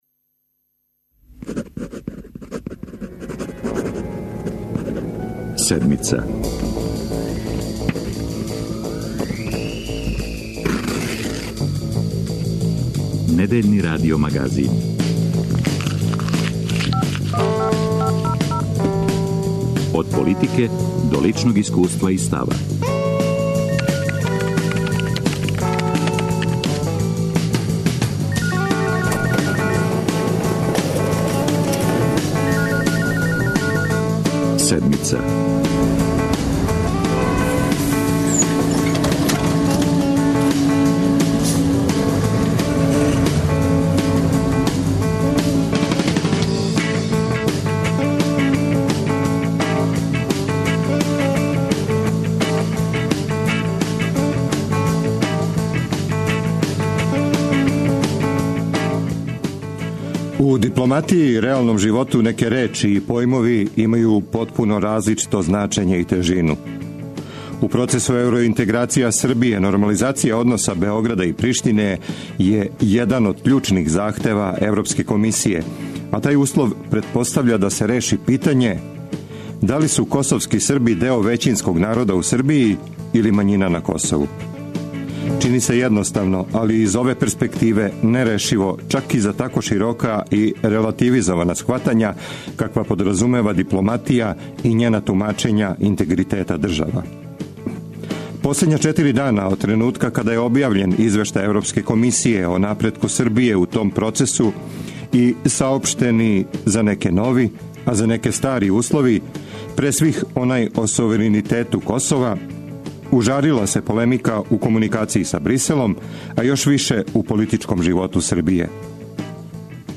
О неким од ових недостатака говори извештај Европске комисије, а коментаришу гости Седмице, представници СНС-а, ДСС-а и ЛДП-а.